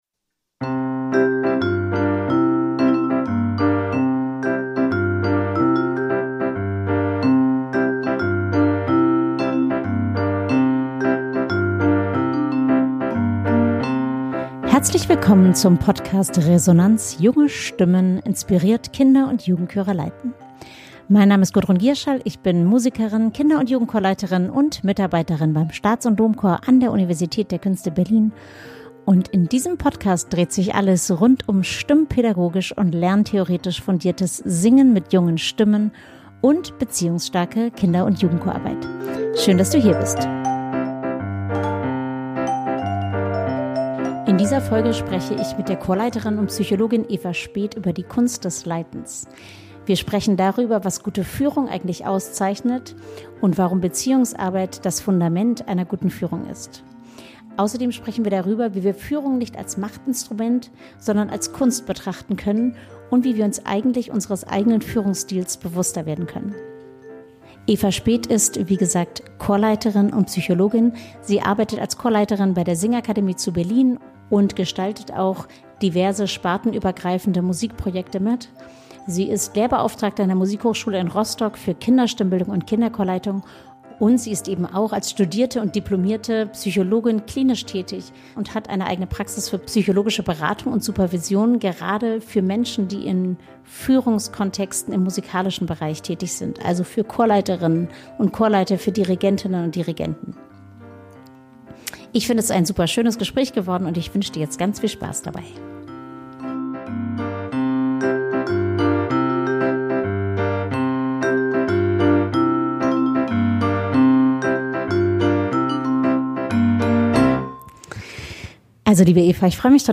#015 - Die Kunst des Leitens - im Gespräch